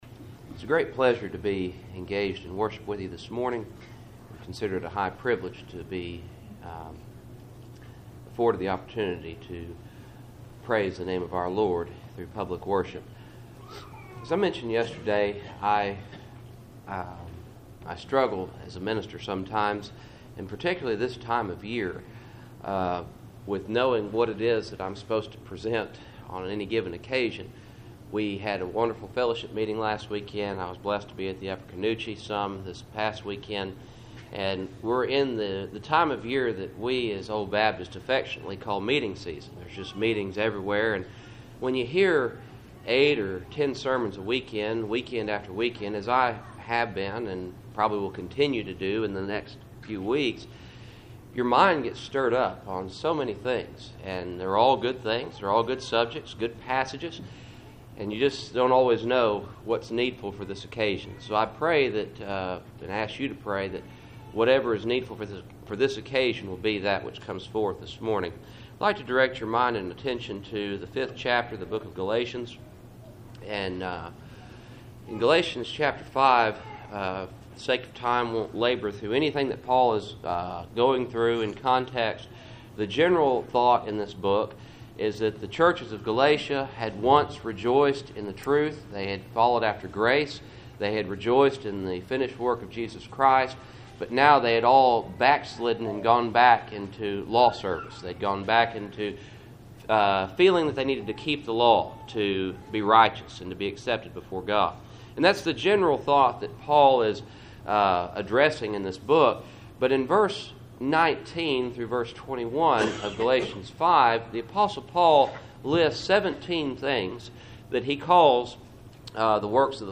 Passage: Galatians 5:22-23 Service Type: Cool Springs PBC Sunday Morning